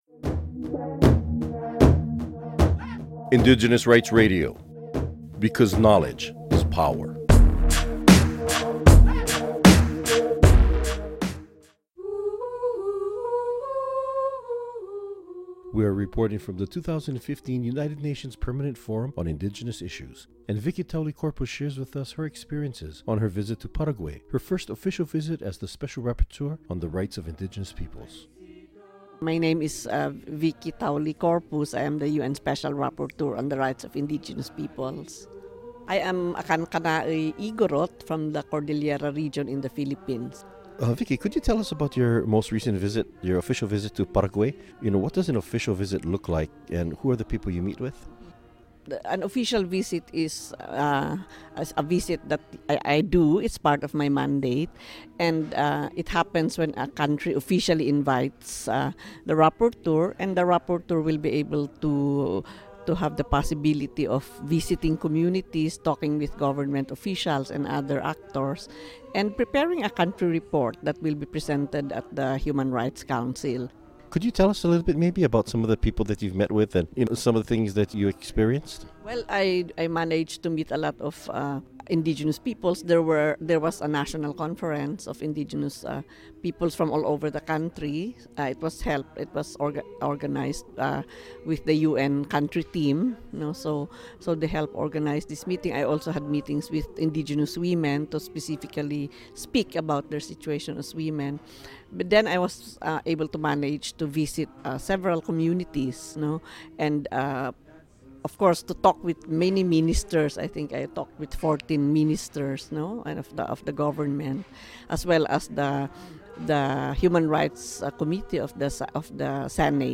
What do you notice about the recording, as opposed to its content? Interviews from the UN Permanent Forum on Indigenous Issues (2015) Cultural Survival recorded these interviews at the UN Permanent Forum on Indigenous Issues.